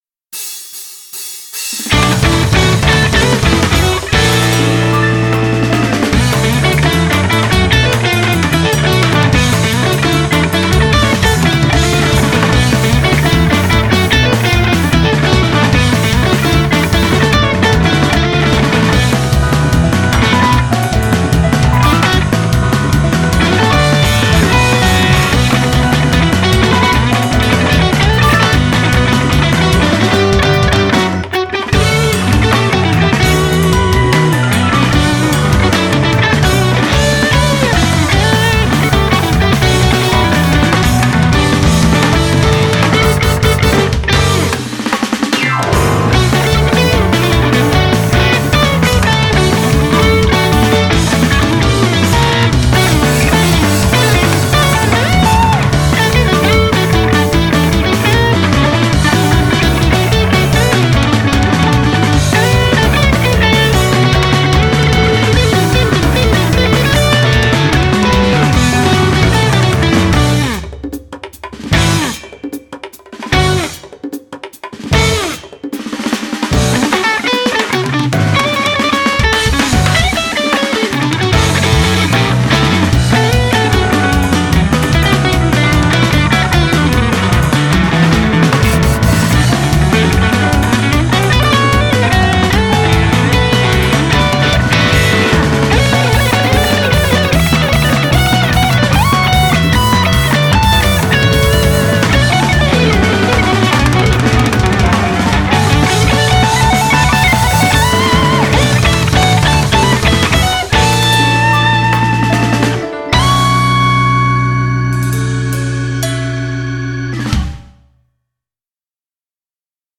BPM300
Audio QualityPerfect (High Quality)
A jazzy song